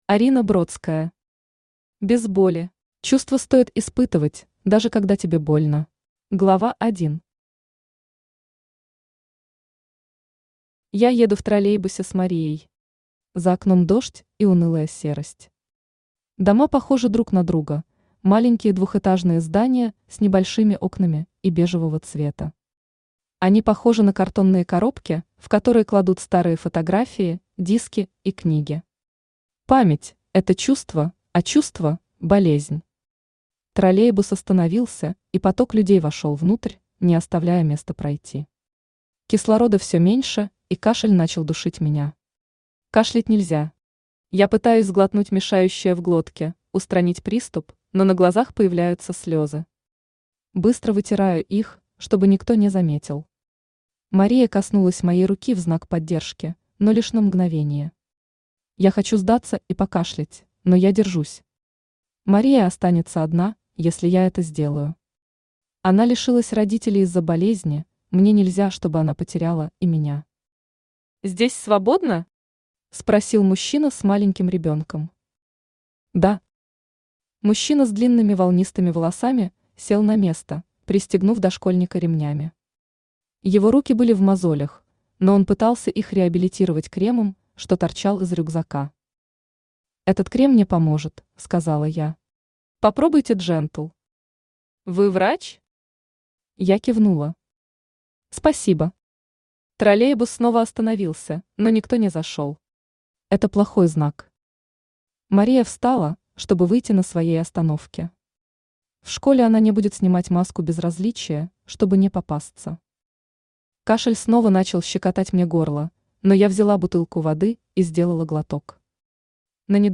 Aудиокнига Без боли Автор Арина Дмитриевна Бродская Читает аудиокнигу Авточтец ЛитРес.